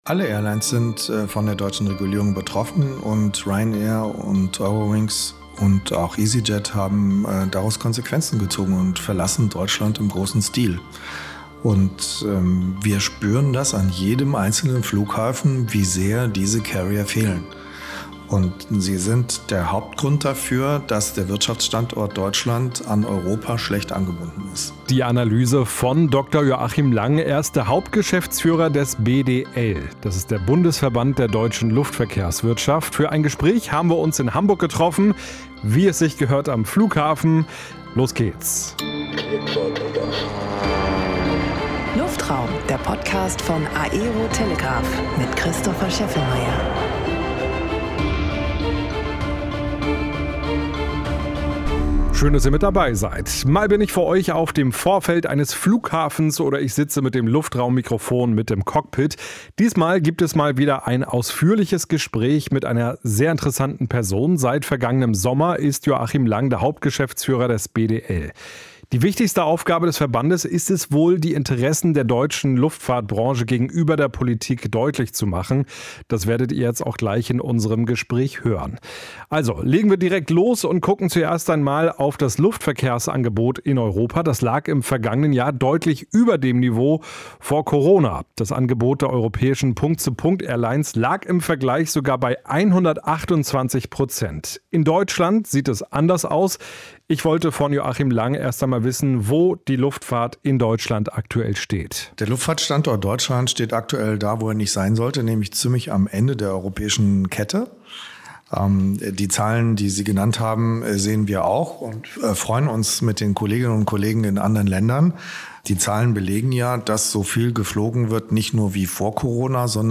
Das ganz Gespräch hört ihr in dieser Episode.